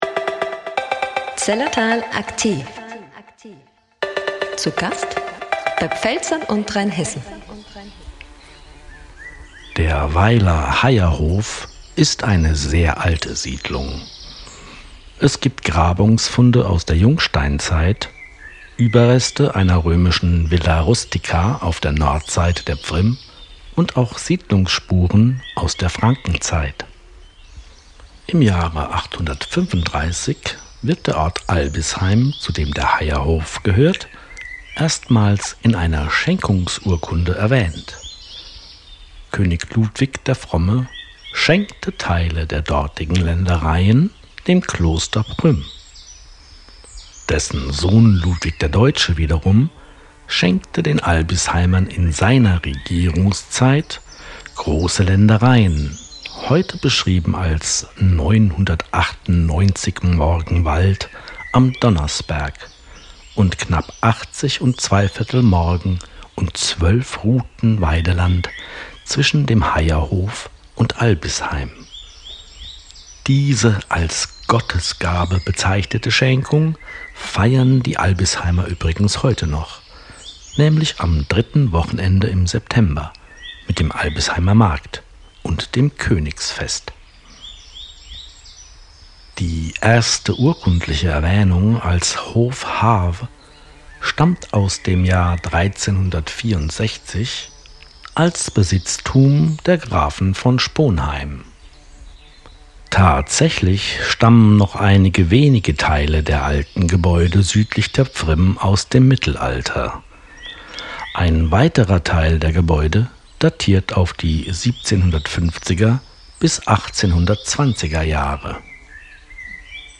AUDIO-GUIDE DER HEYERHOF – Willkommen im Zellertal